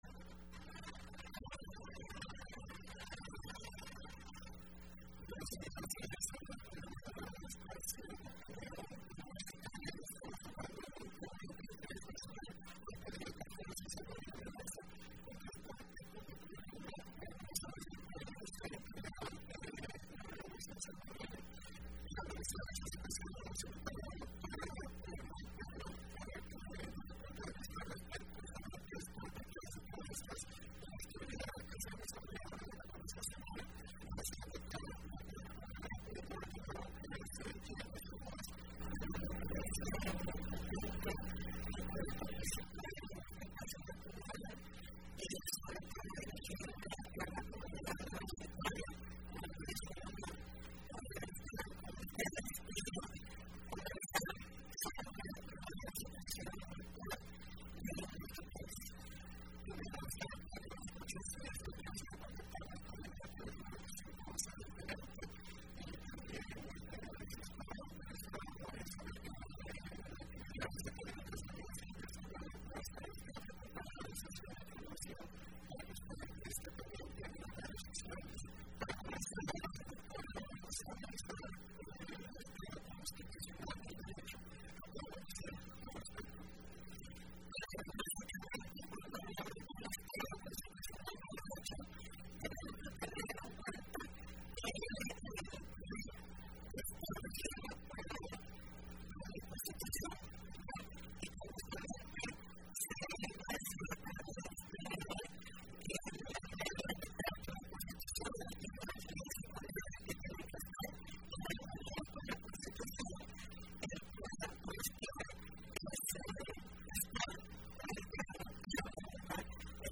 Entrevista Opinión Universitaria (30 de Julio 2015): Inseguridad que atraviesa El Salvador